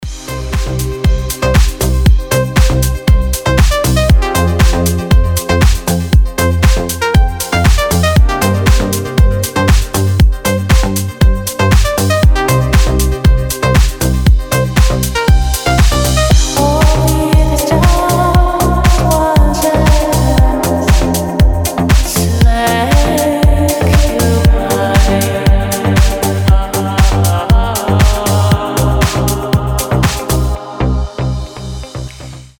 • Качество: 320, Stereo
deep house
женский голос
теплые
Расслабляющая музыка на красивый звонок